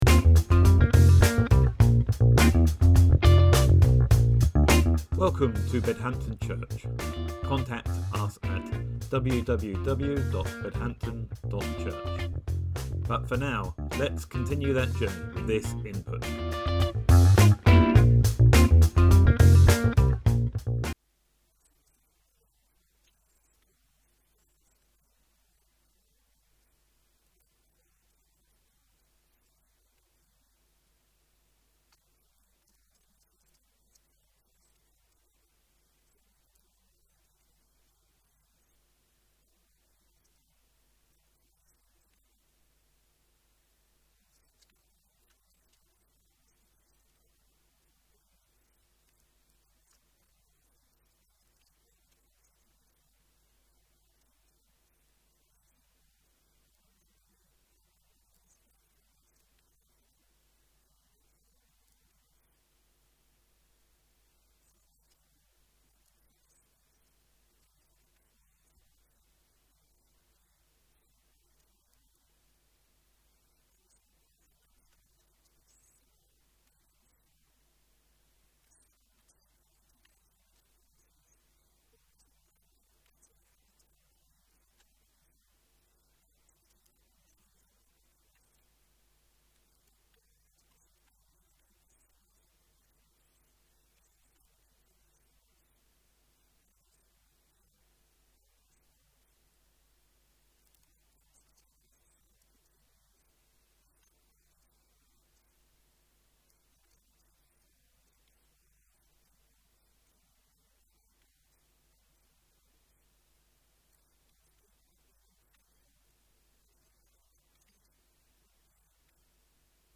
Sermon: Psalm 81.1,10-16